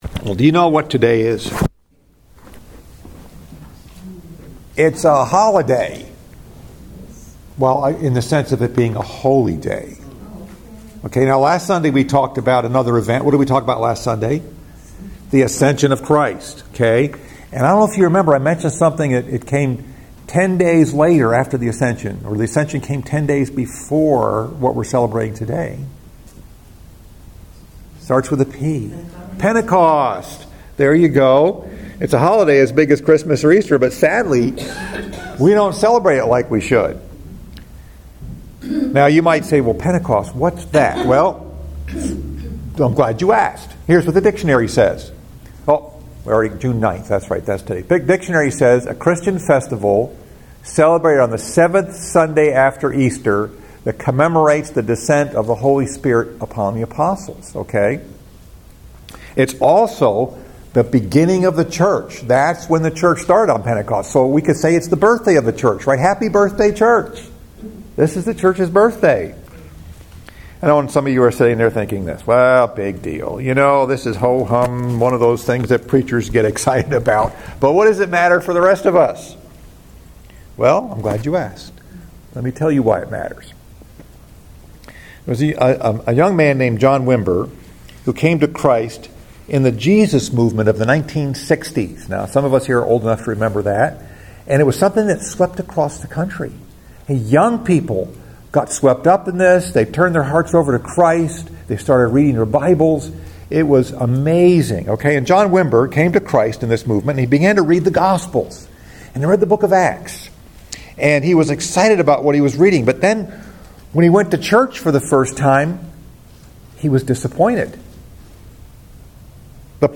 Message: “Awesome Power” Scripture: Acts 2